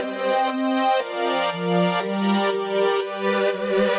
Index of /musicradar/french-house-chillout-samples/120bpm
FHC_Pad C_120-C.wav